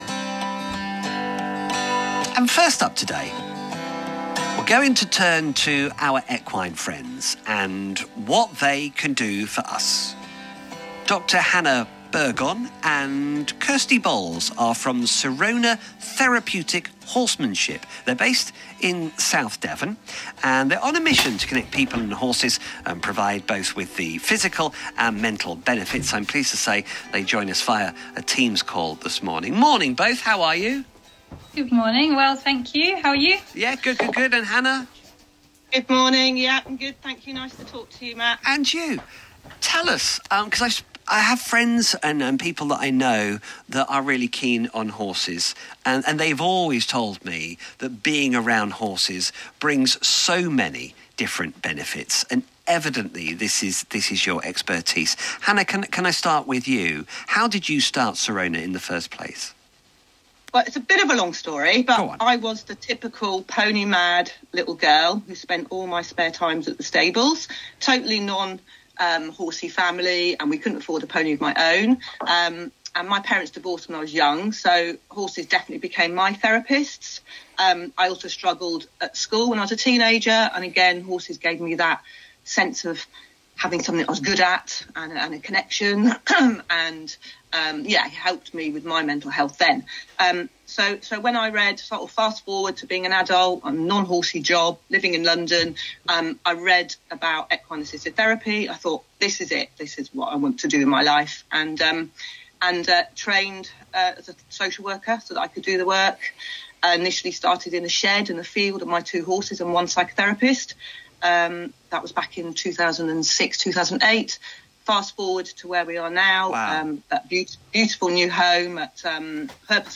Interview with BBC Radio Devon